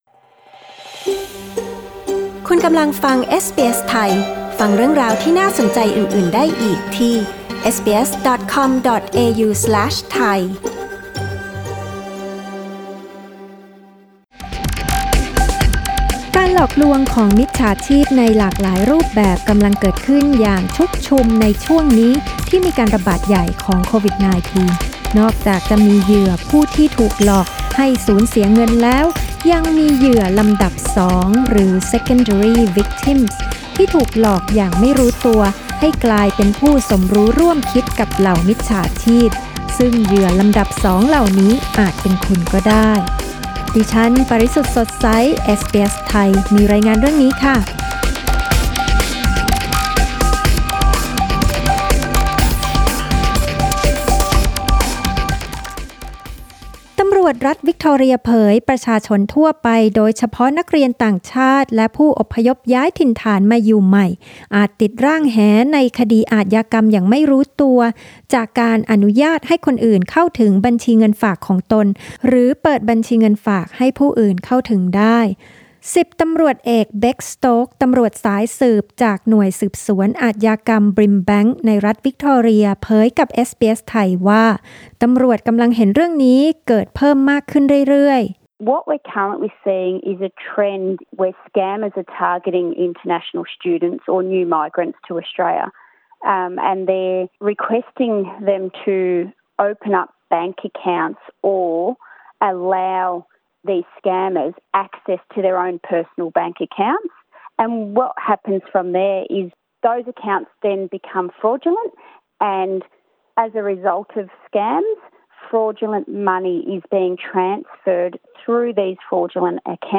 ตำรวจออสเตรเลียเตือนประชาชนให้ระวังติดร่างแหกับอาชญากรรมโดยไม่รู้ตัว จากการถูกมิจฉาชีพหลอกใช้บัญชีเงินฝากของคุณ ตำรวจรัฐวิกตอเรียให้สัมภาษณ์กับเอสบีเอส ไทย แจ้งว่า ประชาชนที่ตกเป็นเหยื่อจำนวนมากเป็นนักเรียนต่างชาติ และผู้ย้ายถิ่นฐานที่เพิ่งมาอยู่ใหม่